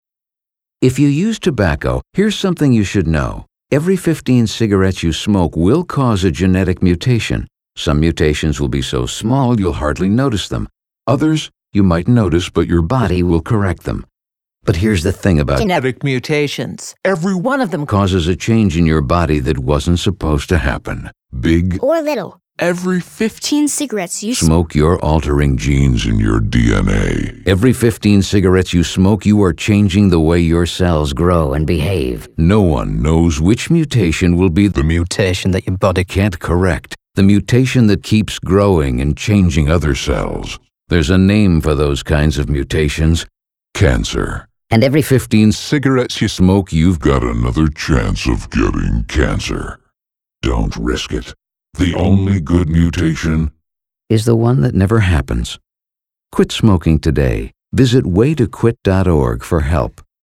“Mutations” Radio Spot Utah Department of Health Facts, stats, and information are even more persuasive when given a creative twist.